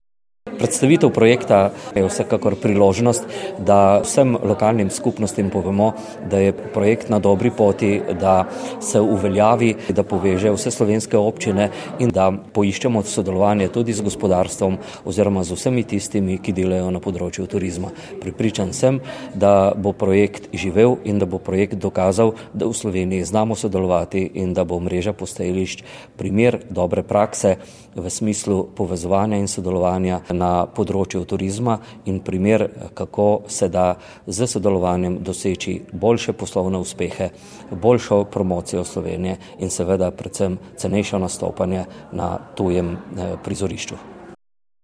tonska izjava Dušana Skerbiša).